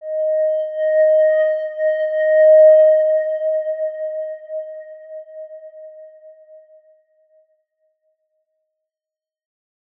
X_Windwistle-D#4-mf.wav